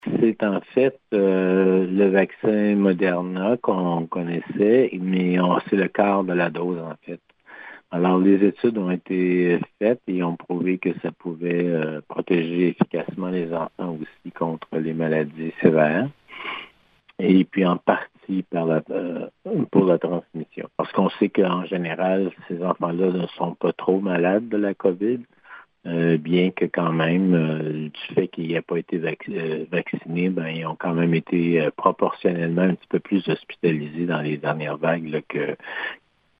Le directeur de la santé publique Yv Bonnier-Viger,